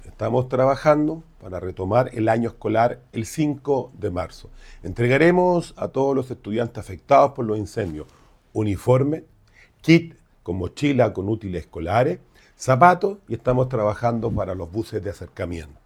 El alcalde, Rodrigo Vera, comentó que estas medidas buscan ayudar de manera concreta y evitar los gastos de marzo; además, están trabajando en proporcionar los uniformes de los escolares.
alcalde-penco.mp3